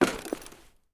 7f0caa168b Divergent / mods / Soundscape Overhaul / gamedata / sounds / material / actor / step / tin2.ogg 32 KiB (Stored with Git LFS) Raw History
tin2.ogg